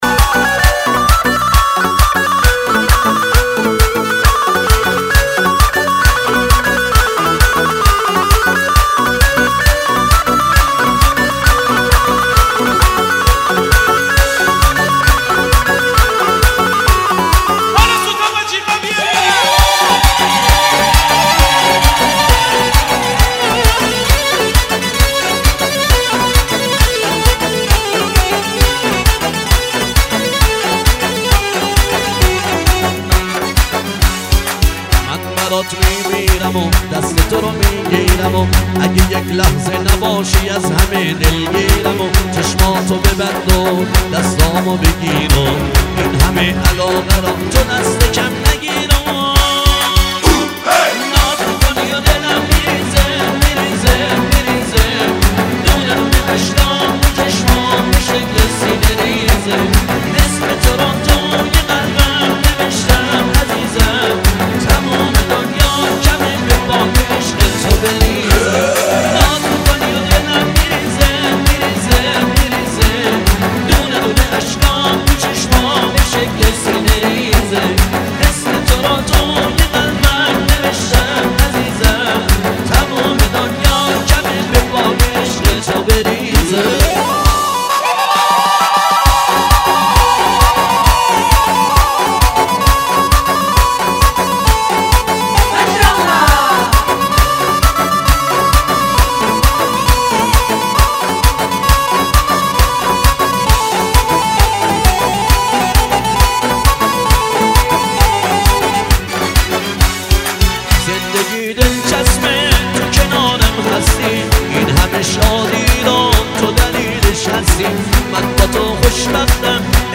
Iranian music